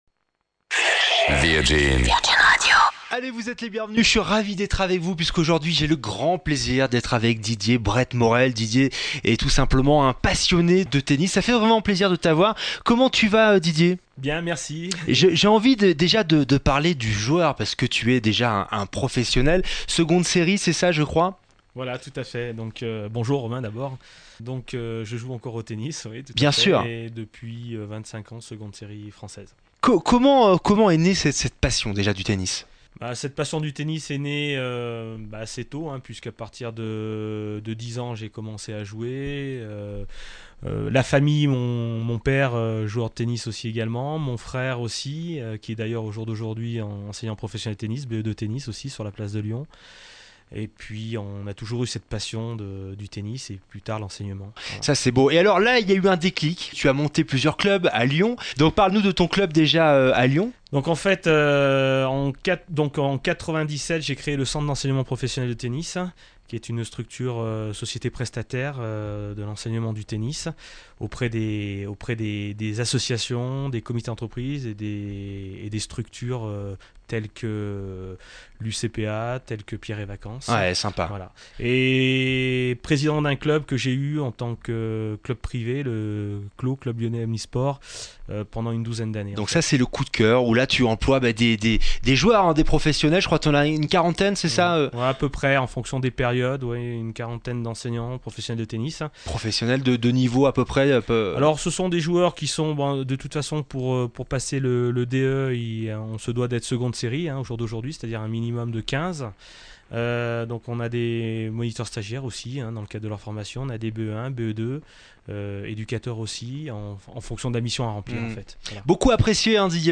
Notre interview par Virgin Radio
Interview-Virgin.mp3